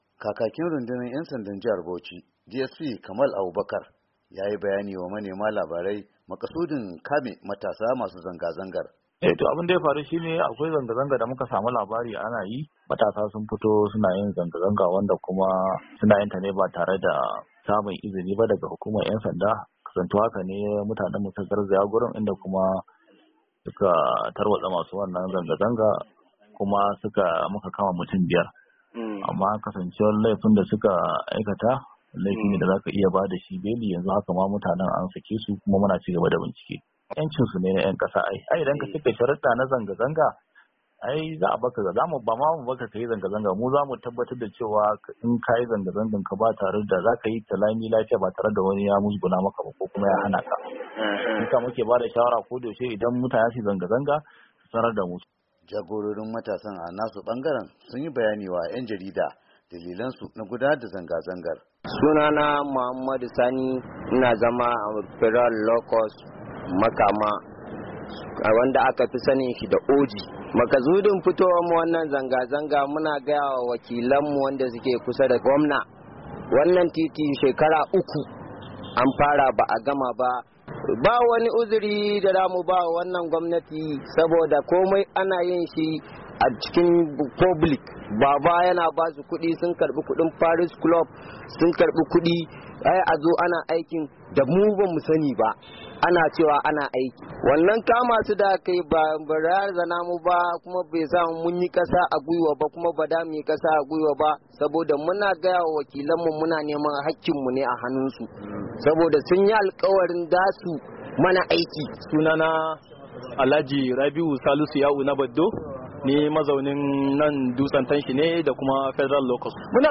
rahoto